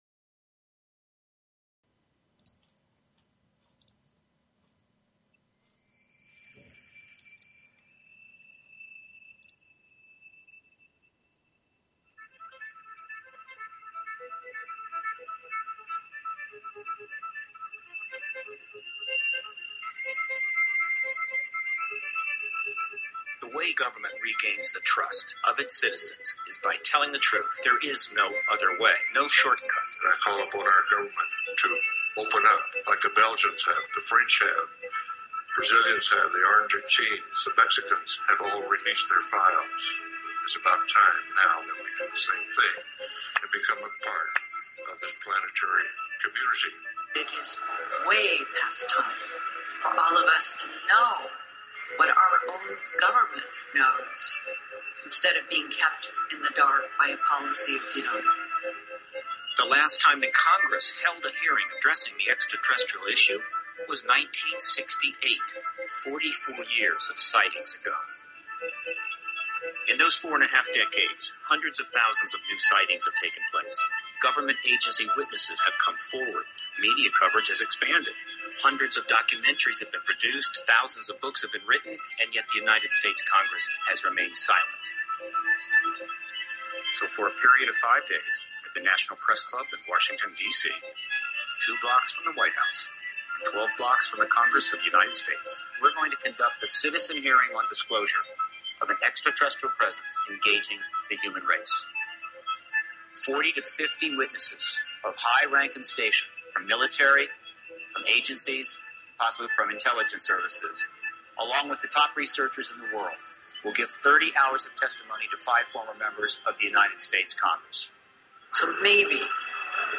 Talk Show Episode, Audio Podcast, Galactic_Roundtable and Courtesy of BBS Radio on , show guests , about , categorized as